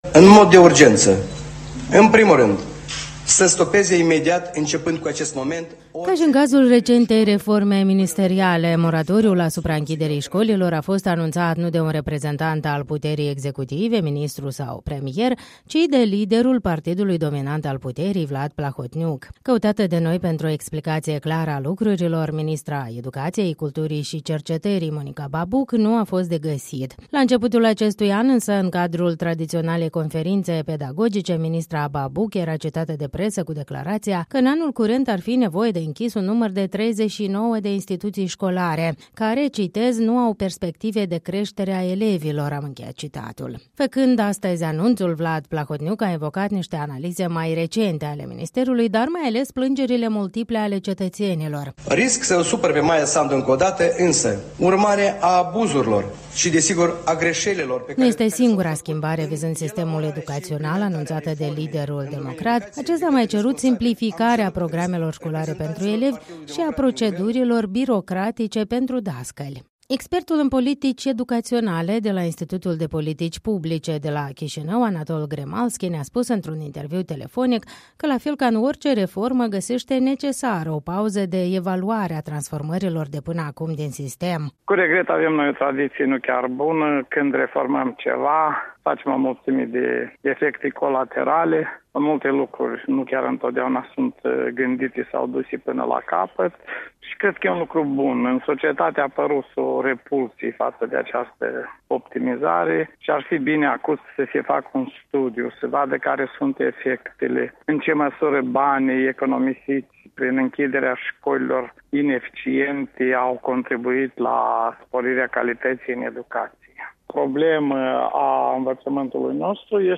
într-un interviu telefonic